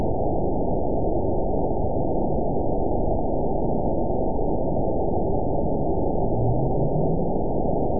event 912022 date 03/15/22 time 15:51:24 GMT (3 years, 2 months ago) score 9.26 location TSS-AB04 detected by nrw target species NRW annotations +NRW Spectrogram: Frequency (kHz) vs. Time (s) audio not available .wav